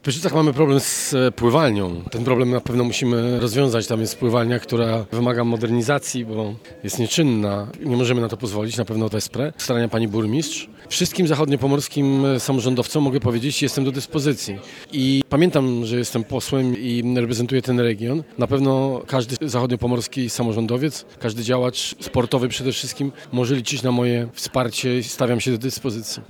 Na pewno każdy zachodniopomorski samorządowiec, każdy działacz sportowy może liczyć na moje wsparcie i stawiam się do dyspozycji – mówi Sławomir Nitras, minister sportu i turystyki